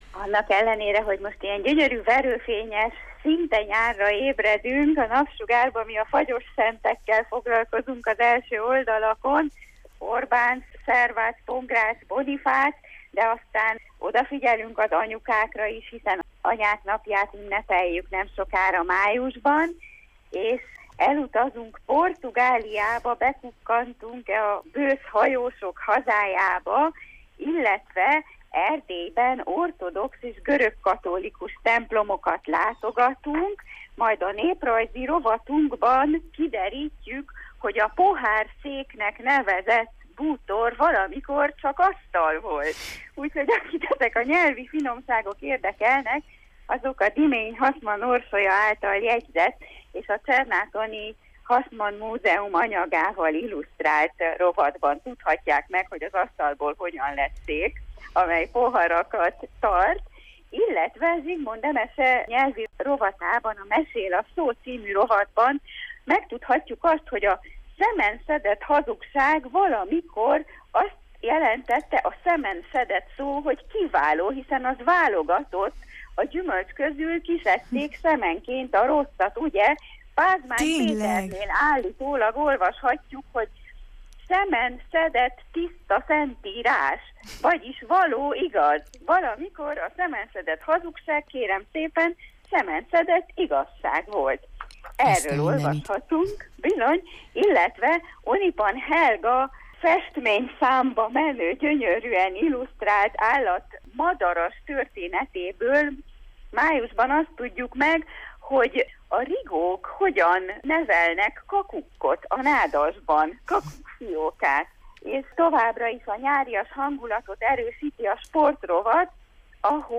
A mai Jó reggelt, Erdély-ben a májusi lap tartalmáról, Anyák napi ajándékötletekről mesél nekünk, és a lapok különkiadásáról, a Rózsás Napsiról is említést tesz.